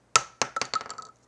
2D-Platformer/Assets/Sounds/Shells/